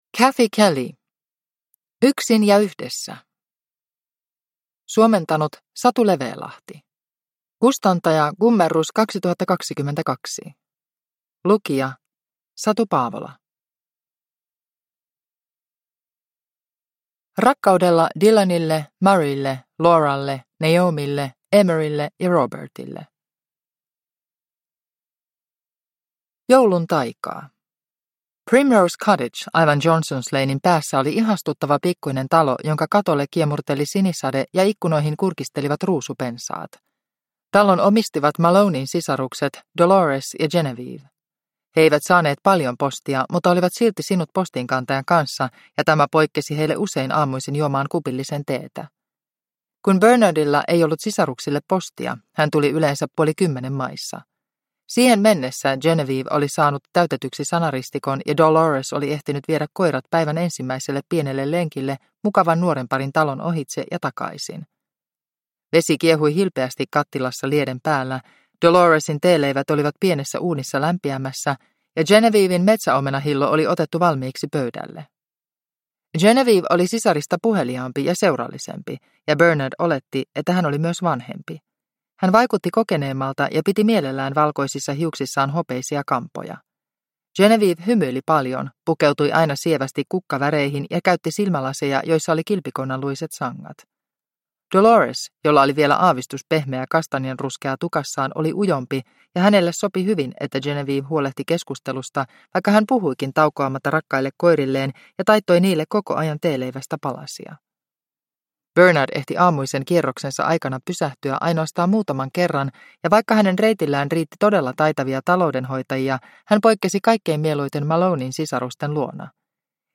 Yksin ja yhdessä – Ljudbok